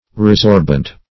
Search Result for " resorbent" : The Collaborative International Dictionary of English v.0.48: Resorbent \Re*sorb"ent\ (-ent), a. [L. resorbens, p. pr. of resorbere.]